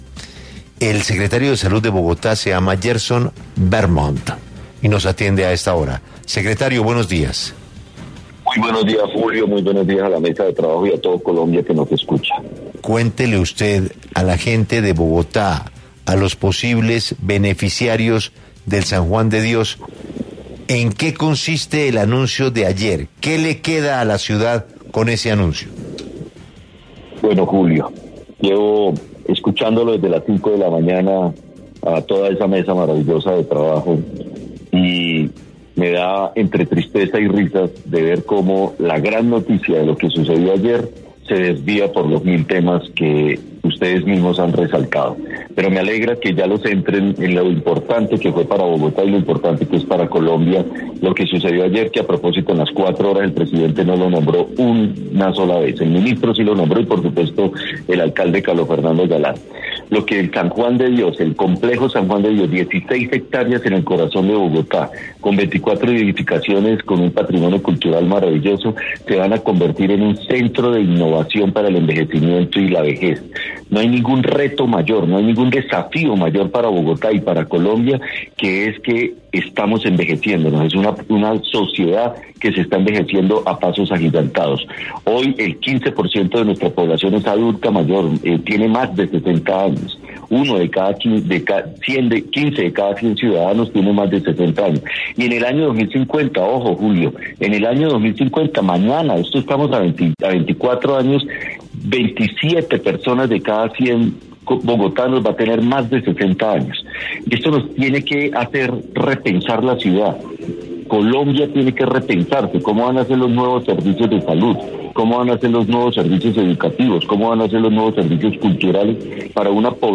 En los micrófonos de 6AM W con Julio Sánchez Cristo habló el secretario de Salud de Bogotá, Gerson Bermont sobre la apuesta estratégica entre el Gobierno y el Distrito en el Complejo Hospitalario San Juan de Dios que se transformará en el Gran Centro de Innovación y Pensamiento para el Envejecimiento y la Vejez, consolidándose como un referente nacional para la investigación, la atención en salud, la formación de talento humano y la construcción de políticas públicas para una sociedad que envejece.